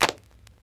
07_Clap_02_SP.wav